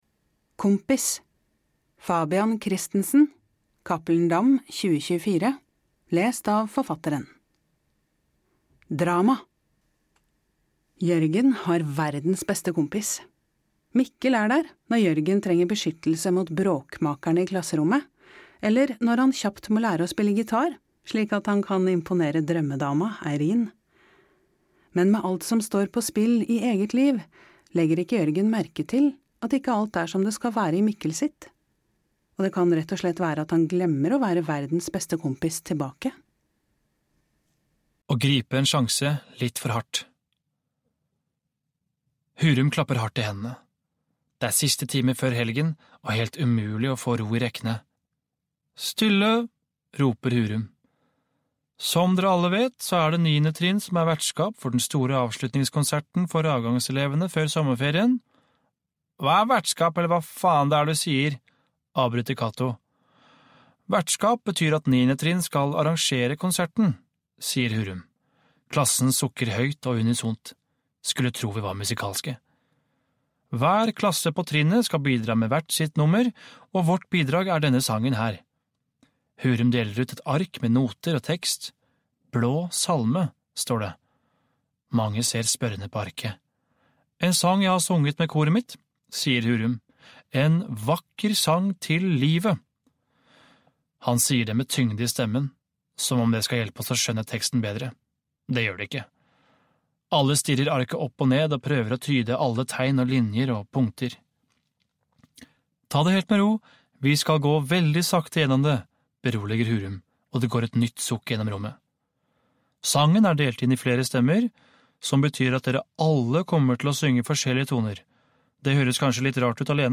Kompis Utdrag Kompis : Last ned lydspor Hvis du har leseutfordringer, kan du låne hele lydboka hos Tibi.